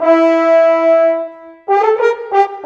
Rock-Pop 07 Horns 04.wav